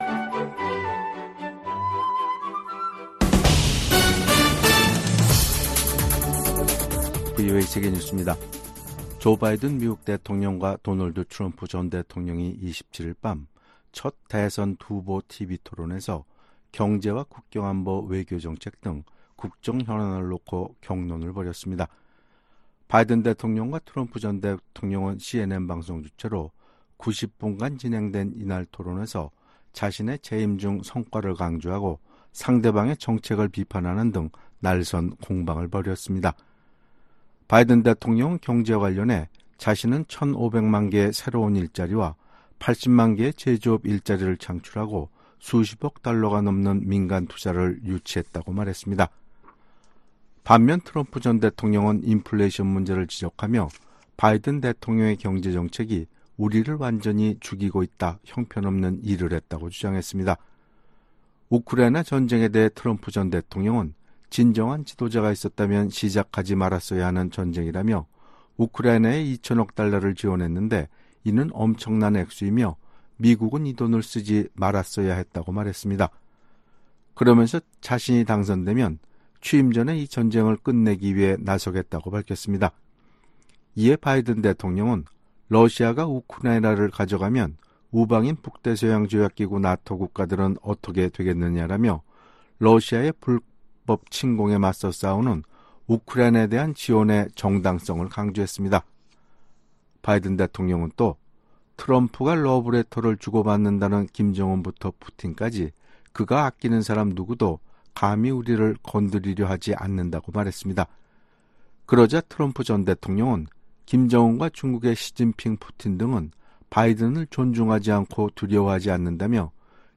VOA 한국어 간판 뉴스 프로그램 '뉴스 투데이', 2024년 6월 28일 3부 방송입니다. 조 바이든 대통령과 도널드 트럼프 전 대통령이 첫 대선 후보 토론회에 참석해 날선 공방을 벌였습니다. 미국 정부는 한국 정치권에서 자체 핵무장론이 제기된 데 대해 현재 한국과 공동으로 확장억제를 강화하고 있다고 강조했습니다. 미 국무부 고위 관리가 최근 심화되고 있는 북한과 러시아 간 협력에 대한 중대한 우려를 나타냈습니다.